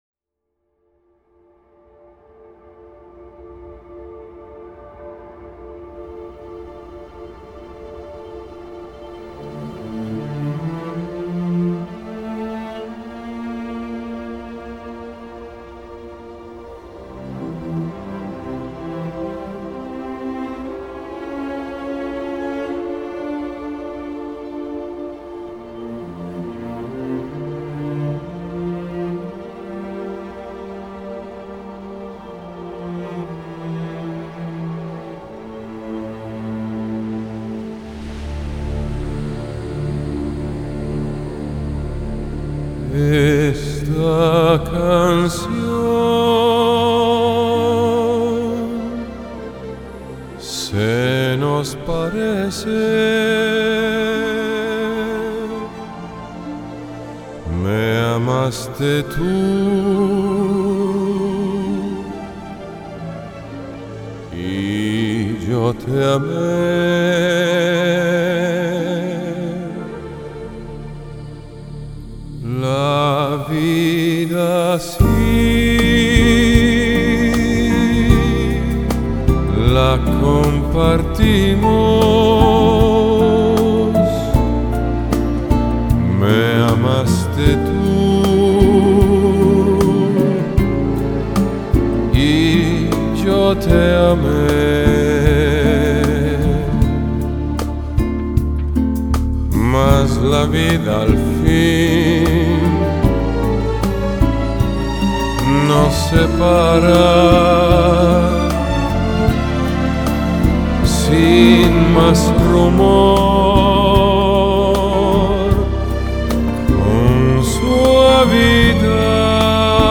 Genre: Vocal, Classical, Crossover